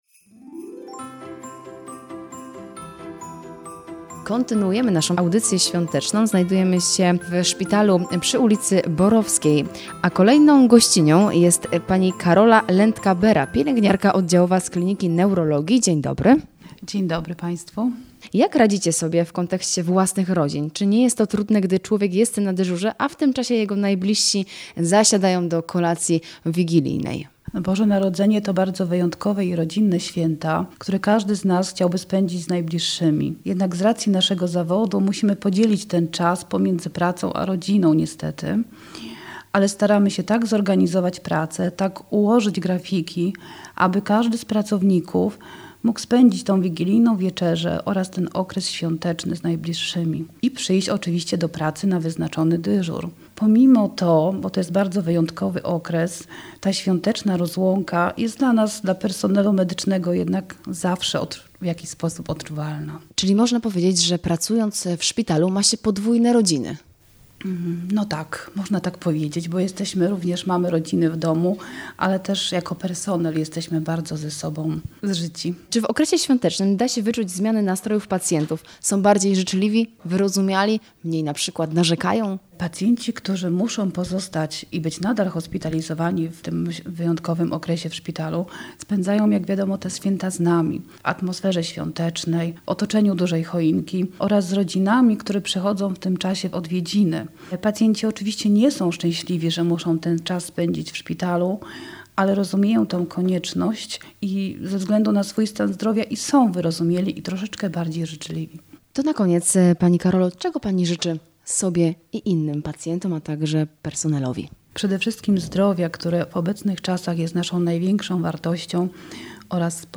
W audycji „Święta na służbie” zaglądamy do Uniwersyteckiego Szpitala Klinicznego we Wrocławiu, aby porozmawiać z lekarzami i pielęgniarkami pracującymi w szpitalu, którzy opowiedzą nam, jak spędzają święta w pracy? Czy czas świąt wpływa na atmosferę?